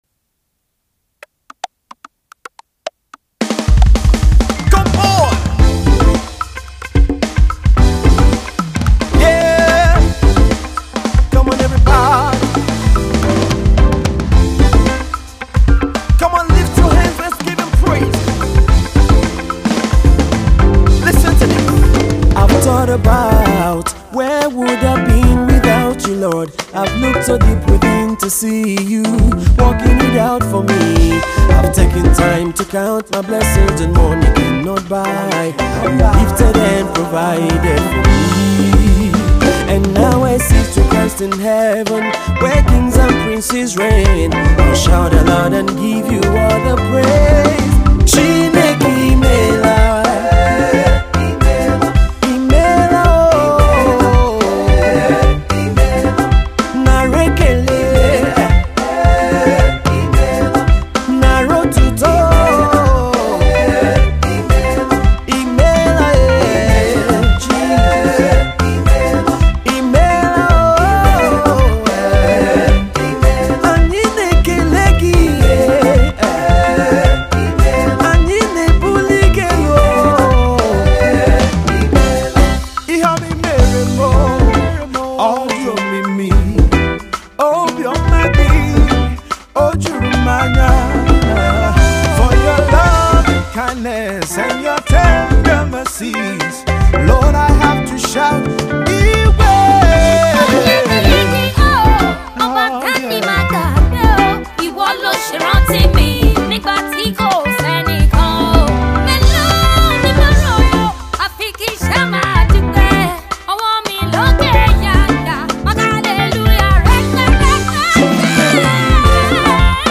two great voices
with a rich Nigerian flavour.